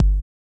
Kick Funk 4.wav